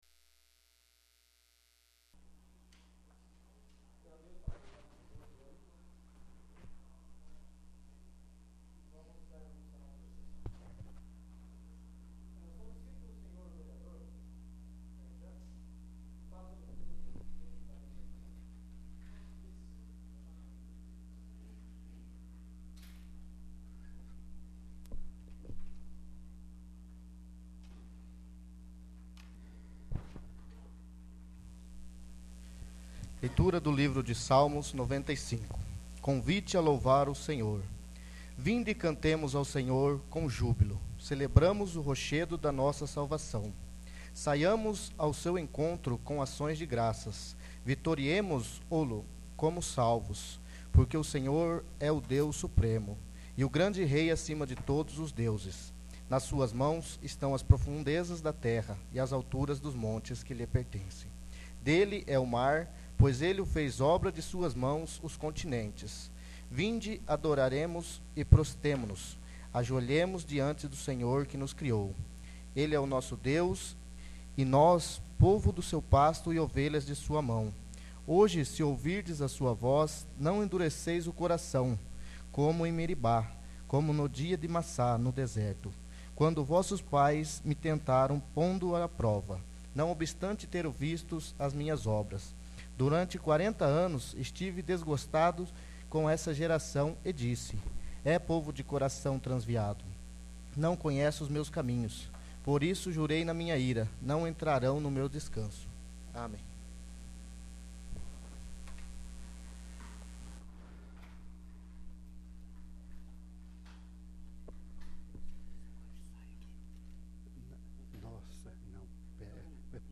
1º. Sessão Ordinária